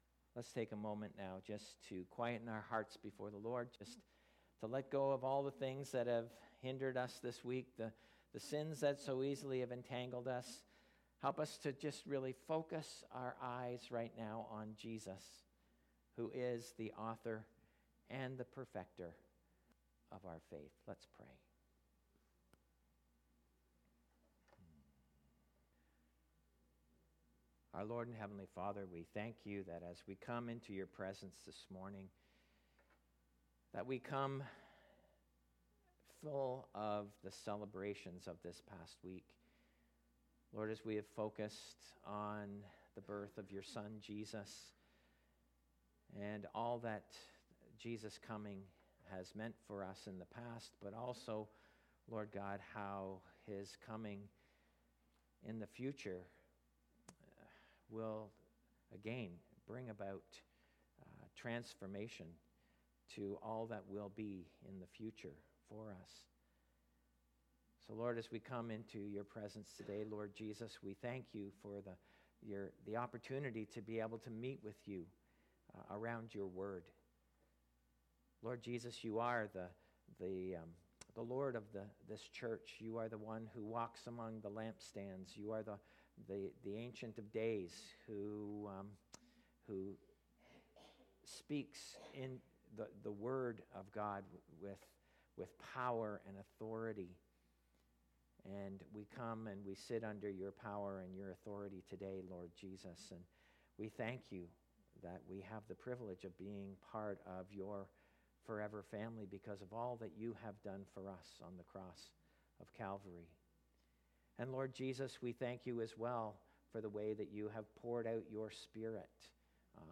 Sermons | Edmison Heights Baptist